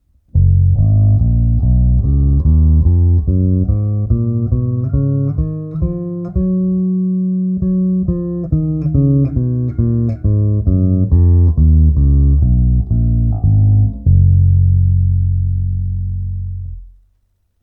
Všechny nahrávky jsou bez dodatečných úprav. Neodstraňoval jsem šum, neupravoval ekvalizaci, jen jsem nahrávky znormalizoval.
Nahrál jsem stupnici nahoru a dolů pro posouzení ovlivnění jak hlubokých, tak vysokých tónů.